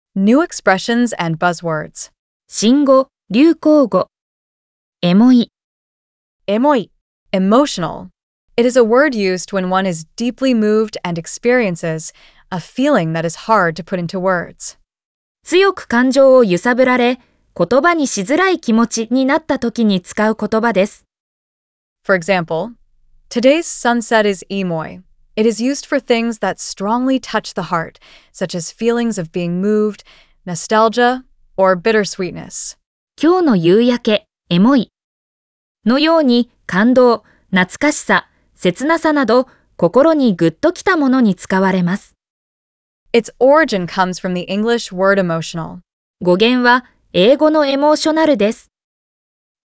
🗣 pronounced: Emoi